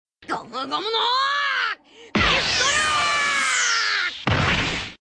Play, download and share Gomu Gomu no Pistol original sound button!!!!